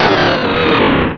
Cri de Drattak dans Pokémon Rubis et Saphir.